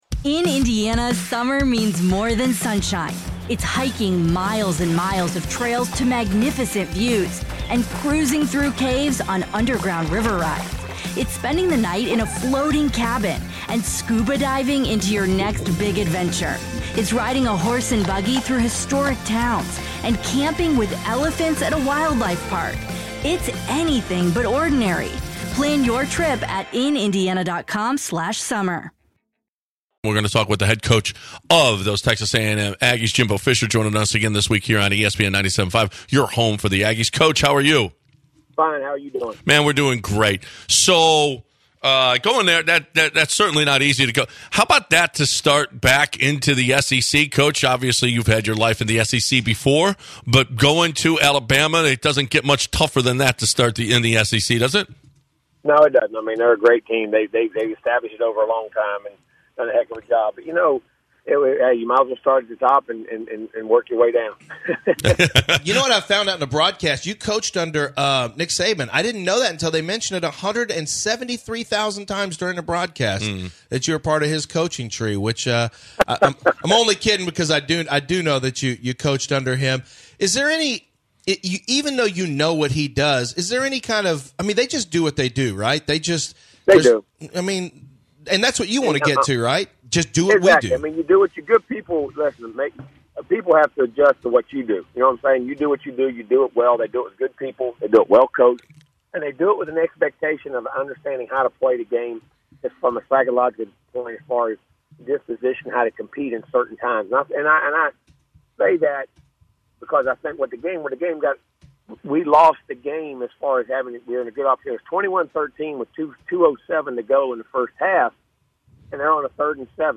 09/25/2018 Texas A&M Head Coach Jimbo Fisher joins The Bench